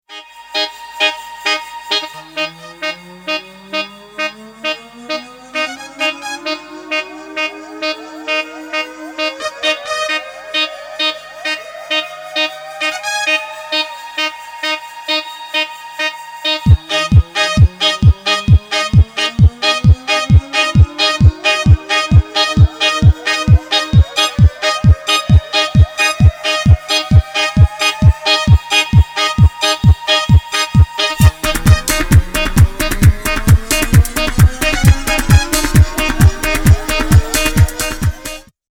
ニュールーツをベースに色んなスタイルでそれぞれのテーマを表現した、